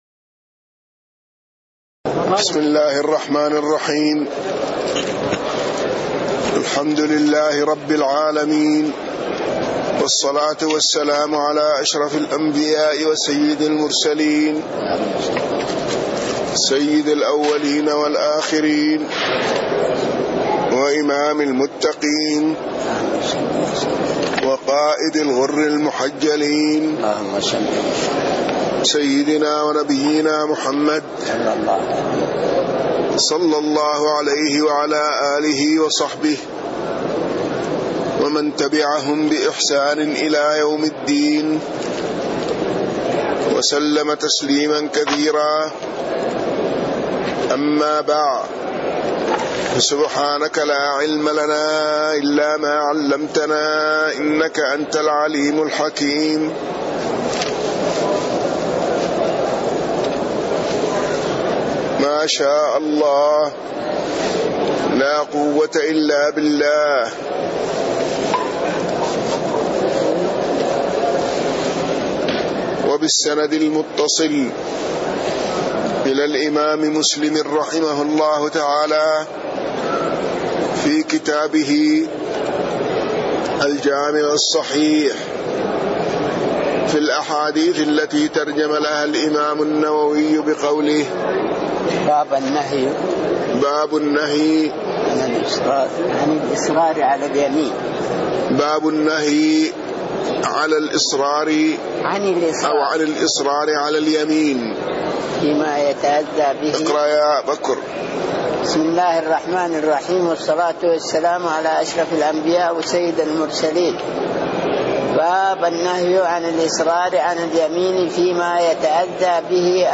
تاريخ النشر ١١ جمادى الأولى ١٤٣٥ هـ المكان: المسجد النبوي الشيخ